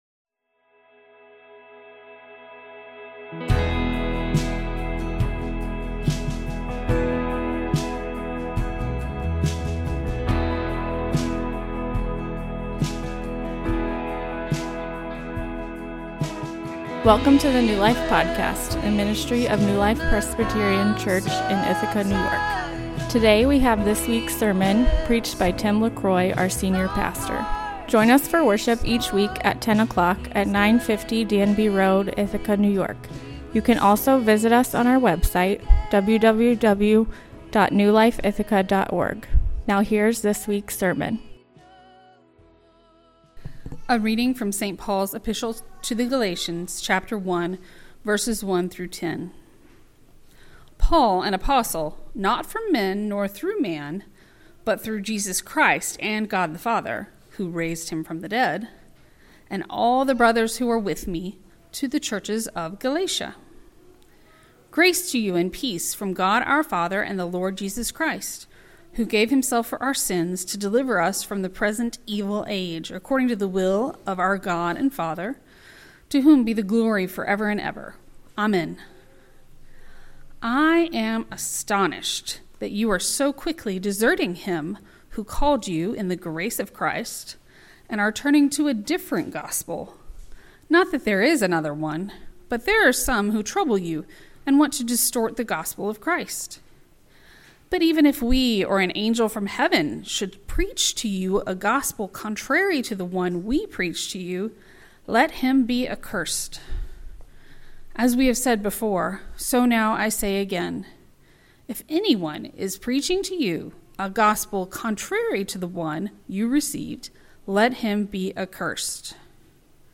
Exposition of Galatians, part 2